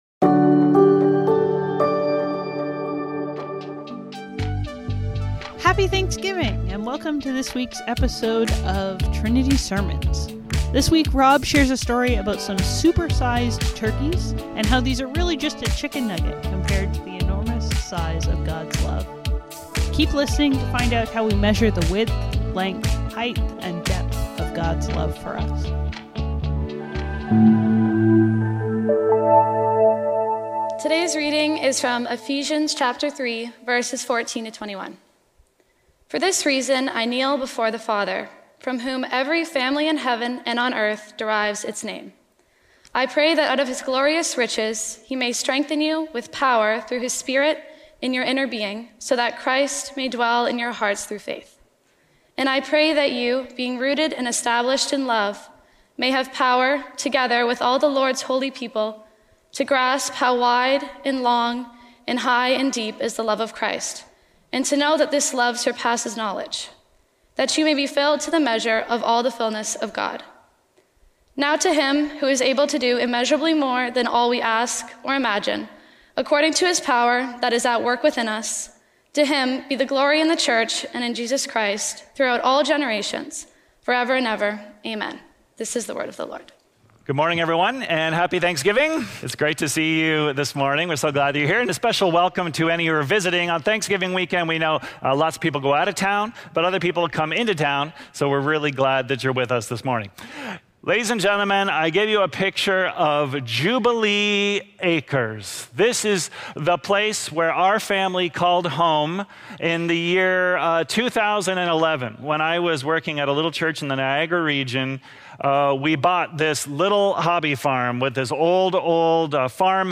Trinity Streetsville - Super Sized | Around The Table | Trinity Sermons